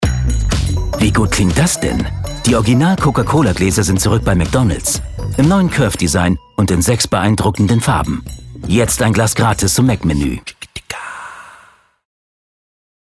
dunkel, sonor, souverän, sehr variabel
Mittel minus (25-45)
Commercial (Werbung)